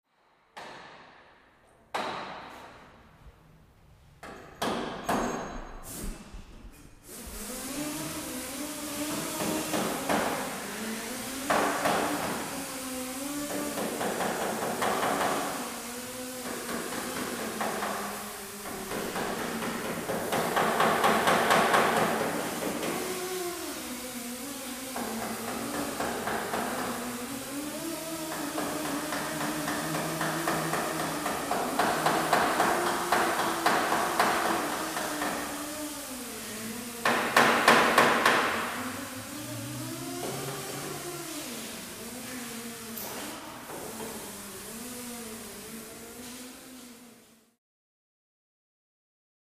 Auto Shop
Automobile Body Shop Ambience, W Pneumatic Tools And Hammering Dents.